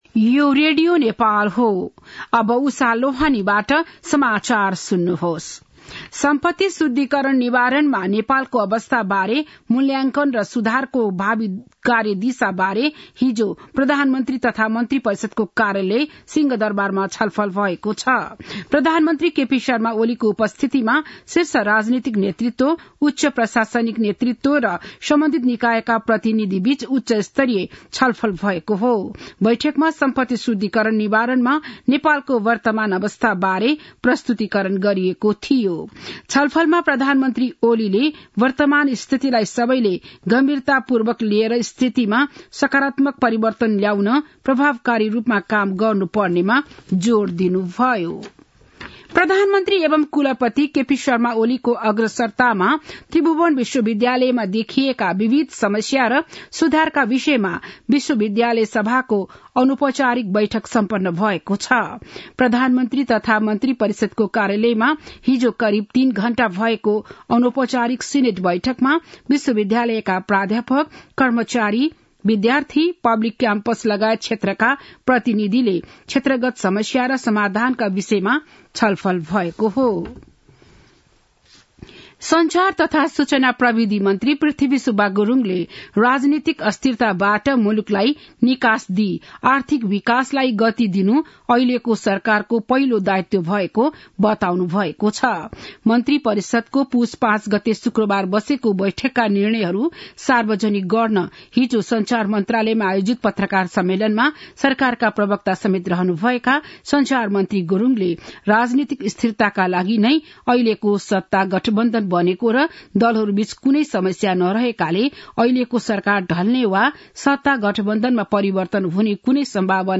बिहान ११ बजेको नेपाली समाचार : ९ पुष , २०८१
11-am-nepali-news-1-18.mp3